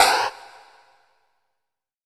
SNARE 027.wav